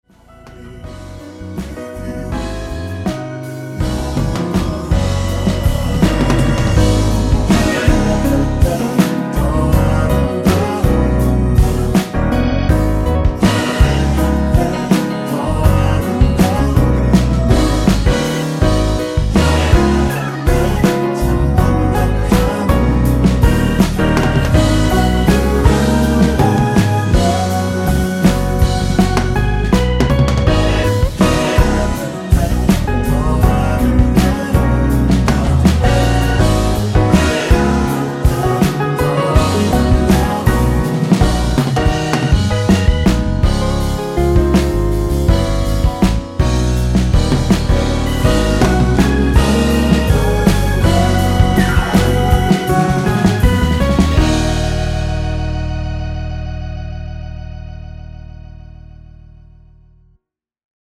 라이브 하시기 좋게 노래 끝나고 바로 끝나게 4분 36초로 편곡 하였습니다.(미리듣기 참조)
원키에서(-2)내린 코러스 포함된 MR입니다.
Bb
앞부분30초, 뒷부분30초씩 편집해서 올려 드리고 있습니다.
중간에 음이 끈어지고 다시 나오는 이유는